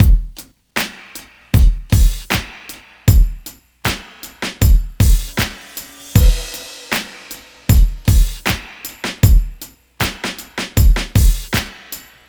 Track 11 - Drum Break 02.wav